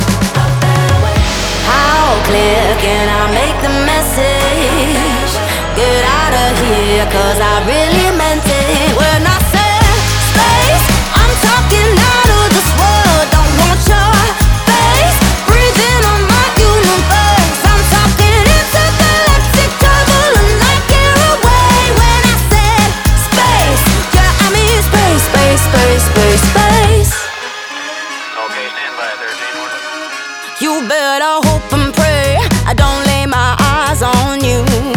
2025-07-15 Жанр: Поп музыка Длительность